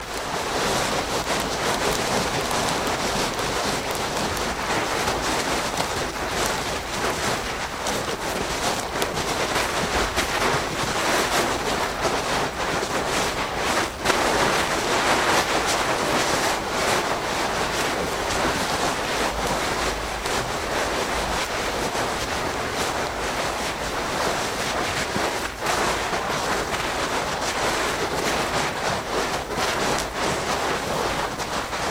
Звуки санок
Сани в оленьей упряжке и северный ветер